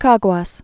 (kägwäs)